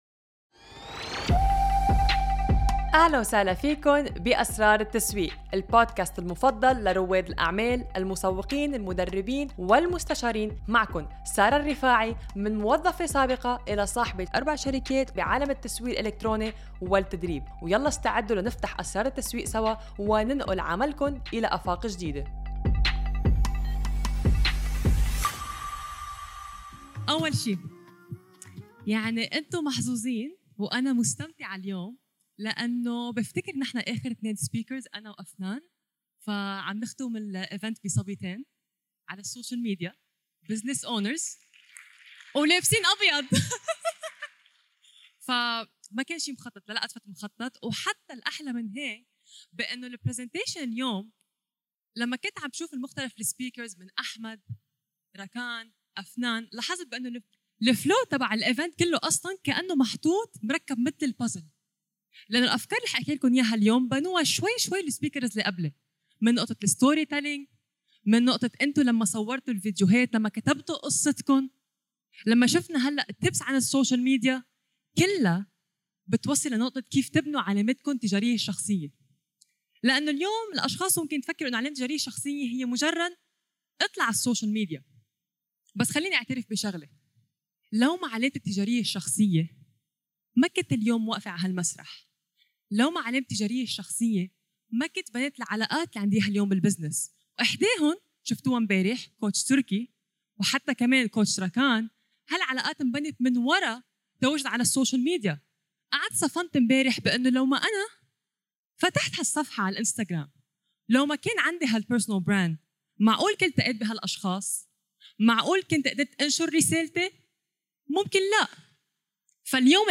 هذه الورشة تمت في اسطنبول في أكتوبر ٢٠٢٤،